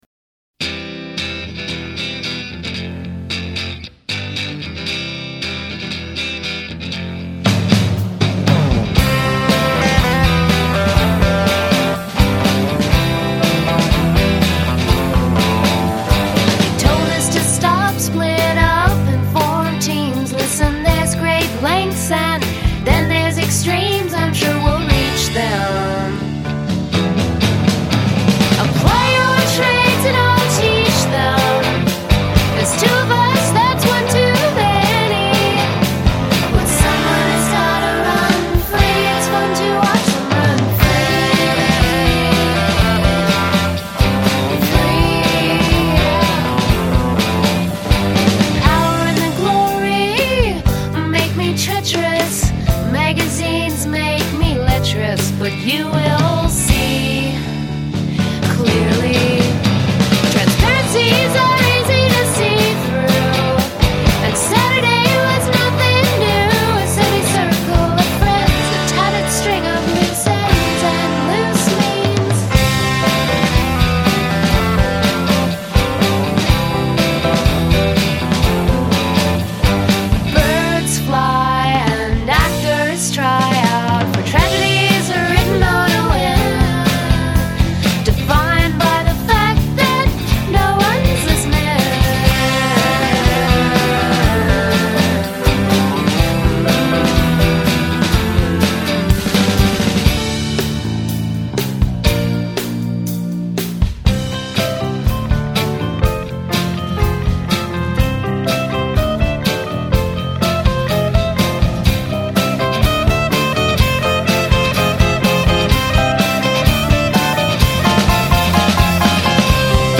la perfección pop.